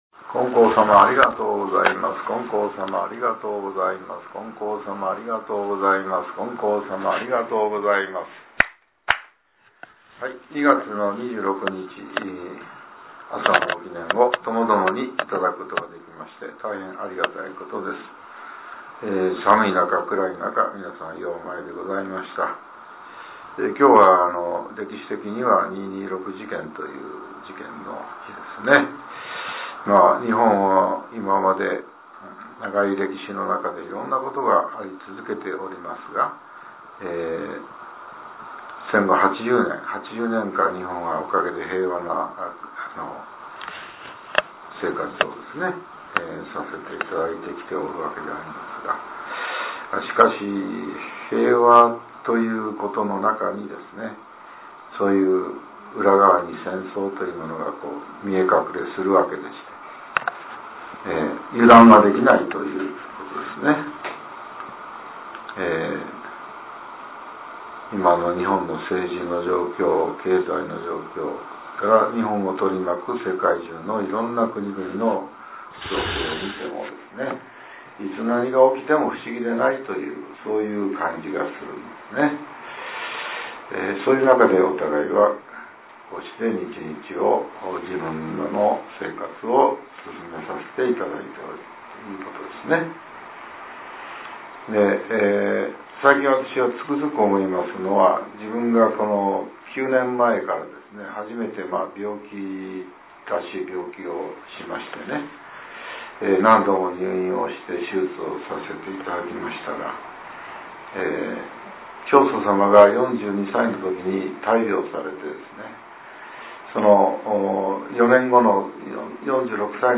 令和７年２月２６日（朝）のお話が、音声ブログとして更新されています。